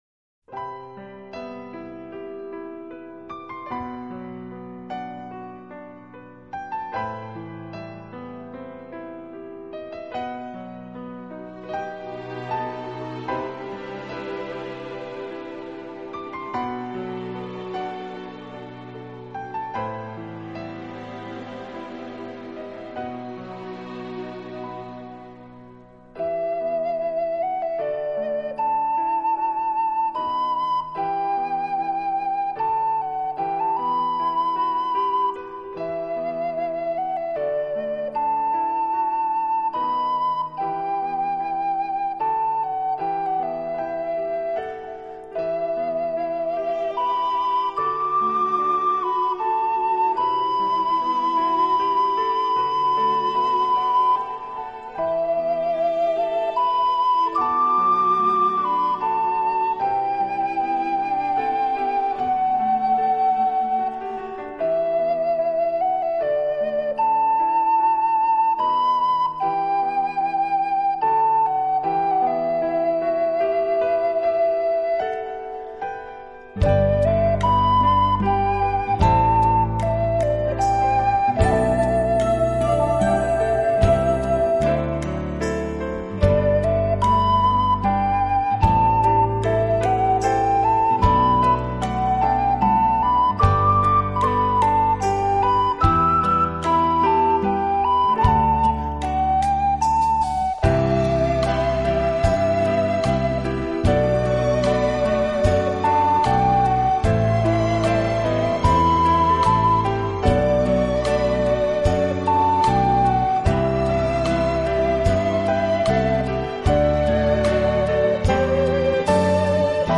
陶笛